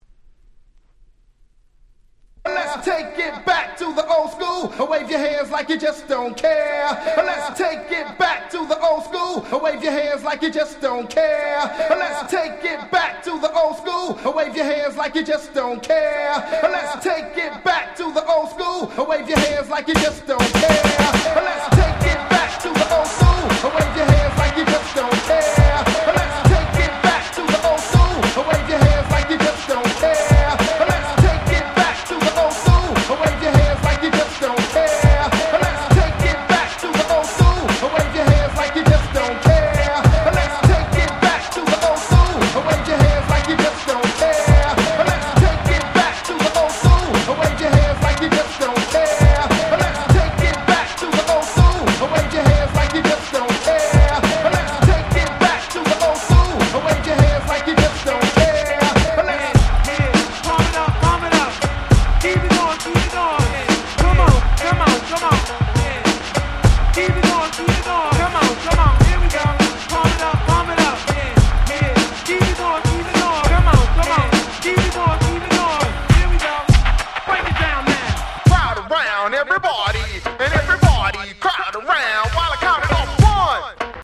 あまり教えたくなかった鬼使えるParty Tracks !!
パーティートラック アゲアゲ  90's